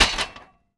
metal_hit_small_03.wav